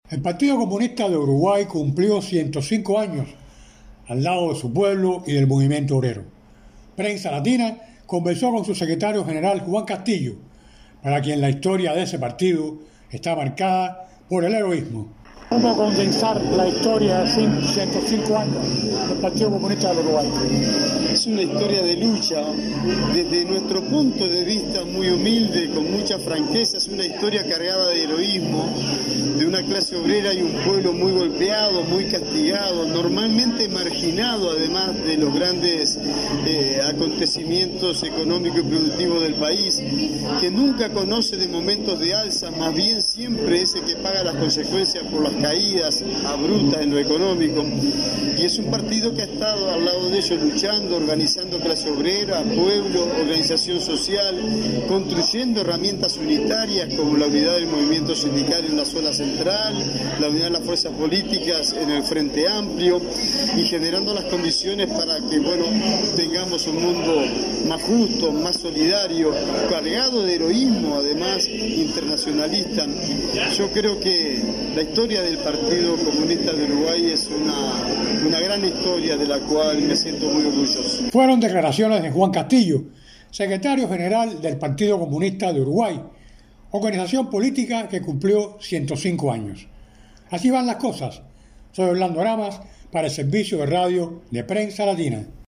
El Partido Comunista de Uruguay cumplió 105 años al lado de su pueblo y del movimiento obrero. Prensa Latina conversó con su secretario general, Juan Castillo, para quien la historia de ese partido está marcada por el heroísmo.